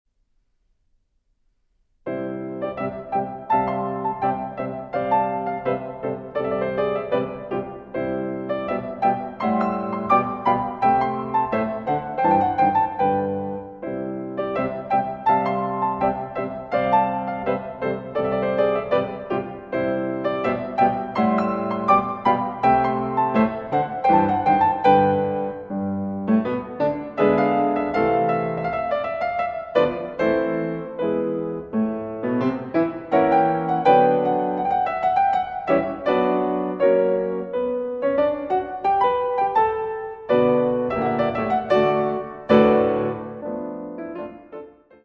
Moderato, ma marcato